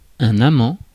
Ääntäminen
IPA: [a.mɑ̃]